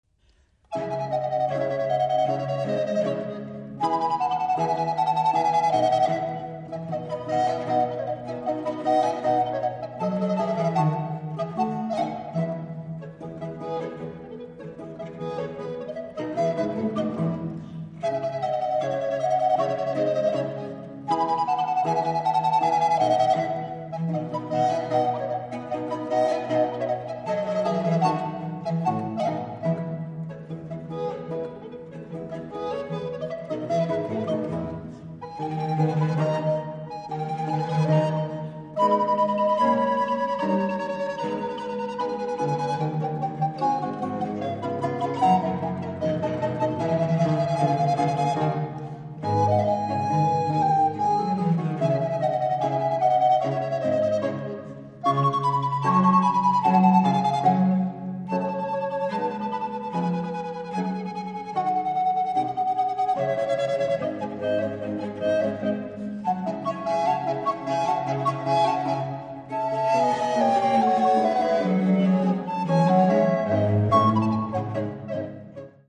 recorders
viola da gamba
archlute
for two recorders and basso continuo